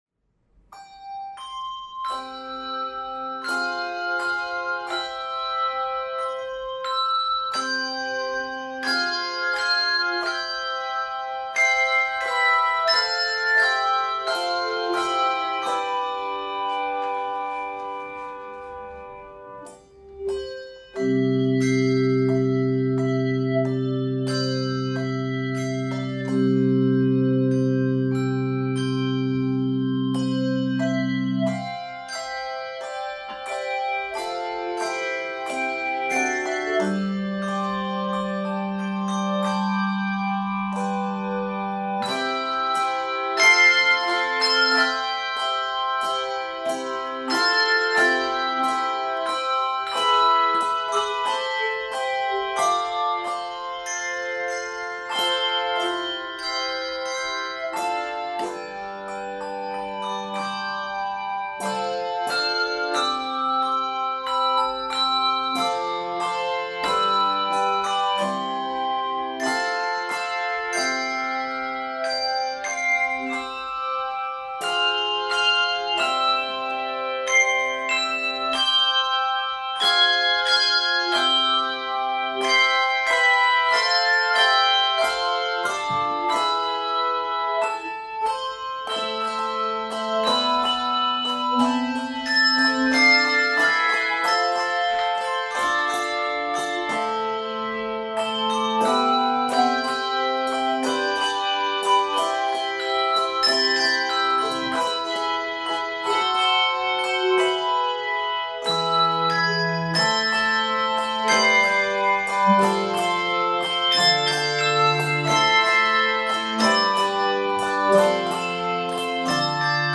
Full rich chords make this a piece that is easy to prepare!